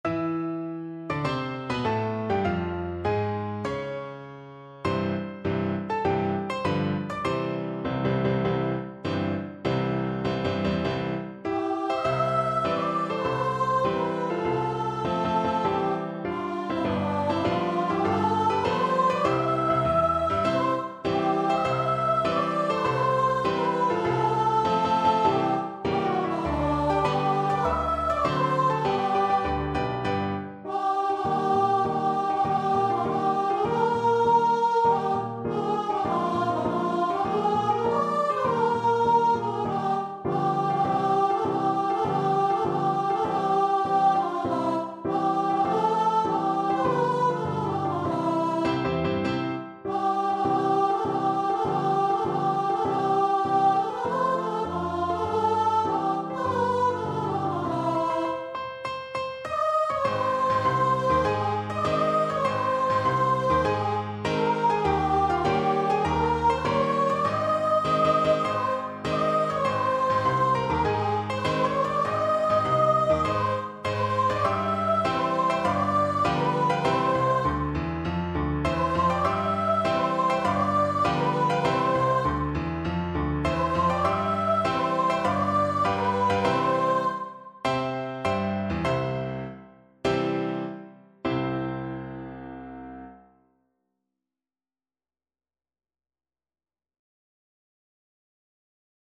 D5-E6
4/4 (View more 4/4 Music)
Marcial
Traditional (View more Traditional Voice Music)